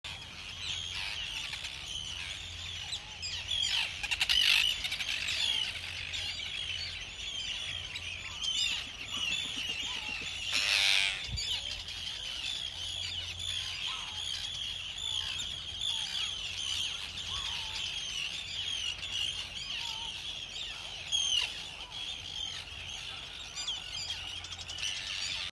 Isle of May Colonies
Listen to the Isle of May during the breeding season:
isle-may-colonies